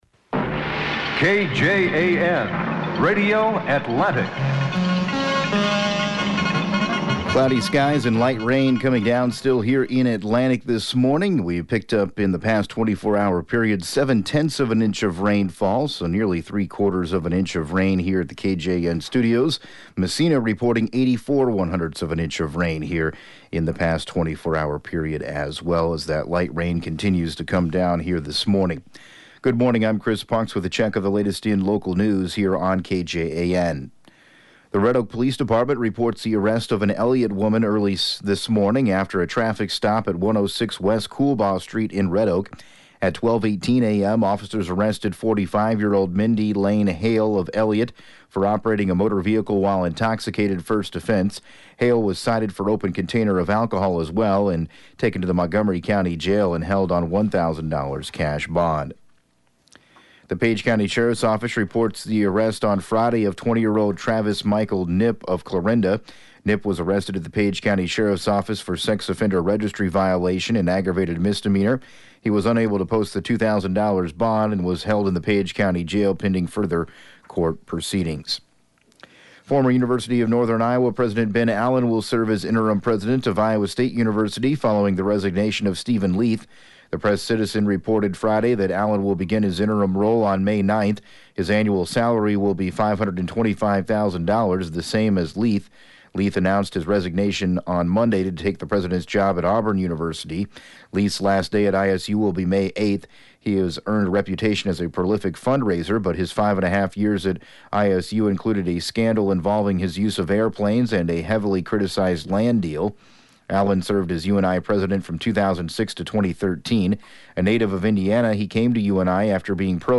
7AM Newscast 03/25/2017